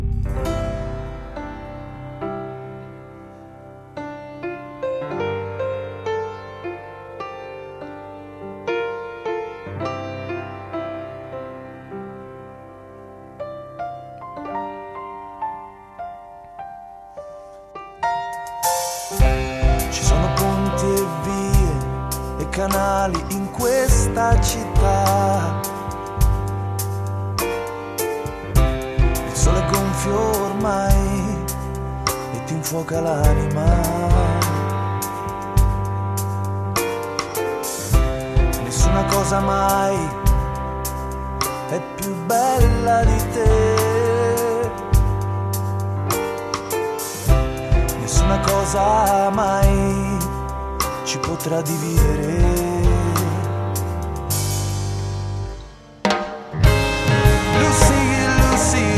Italian prog band
melodic progressive concept albums